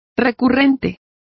Complete with pronunciation of the translation of recurrent.